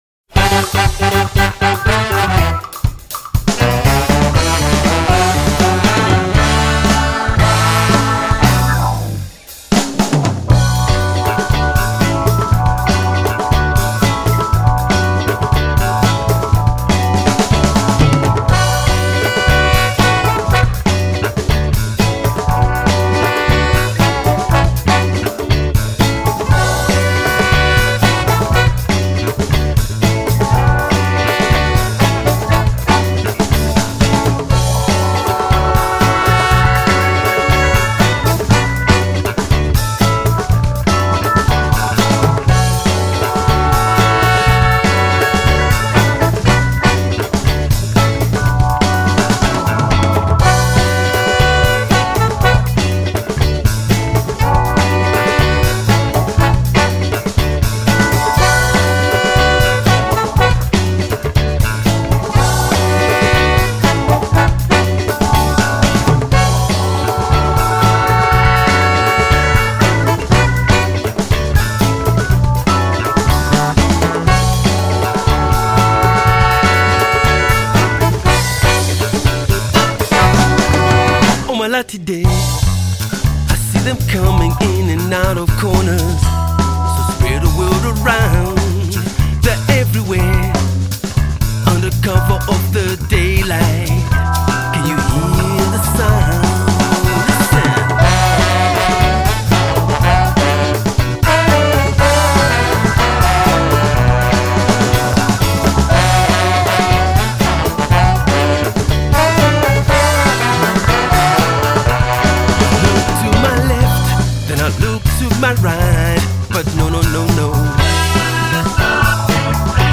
Tight horn riffs, dansable grooves, catchy songs.